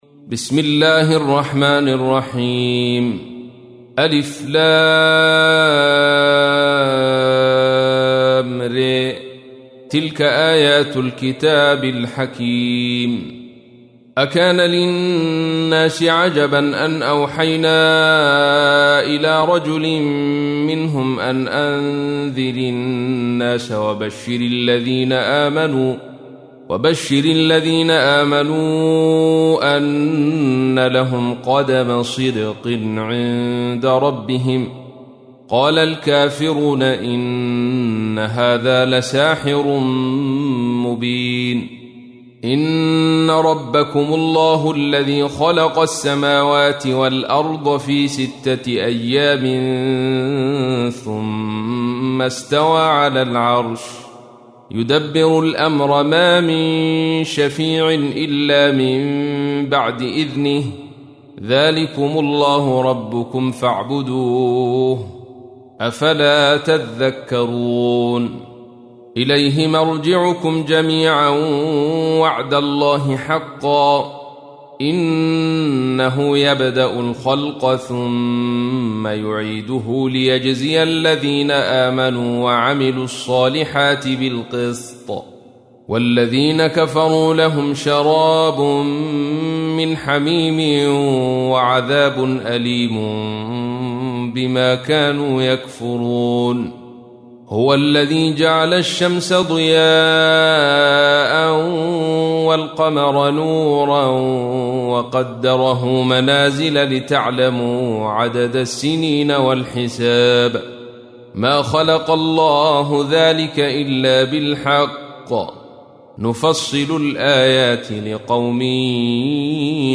تحميل : 10. سورة يونس / القارئ عبد الرشيد صوفي / القرآن الكريم / موقع يا حسين